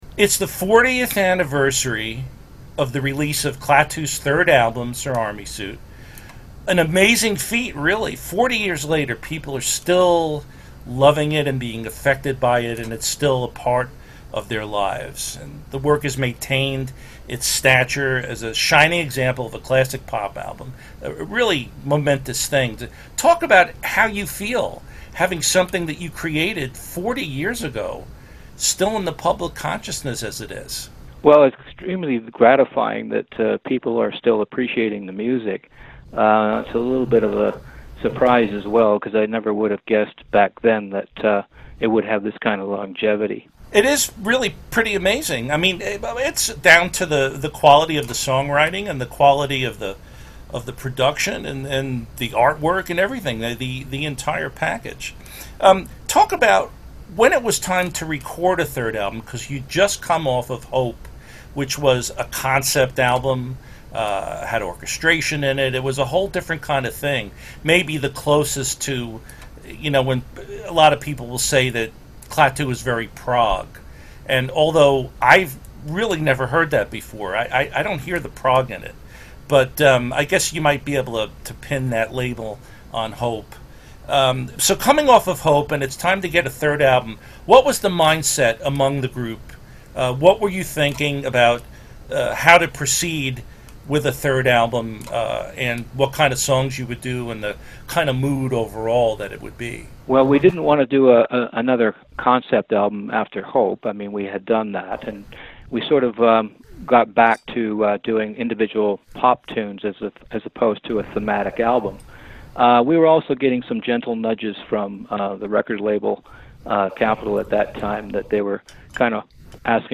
Click on the photos below to hear what they have to say about one of the great melodic pop albums of our time (then right-click on the stream graphic to download each interview).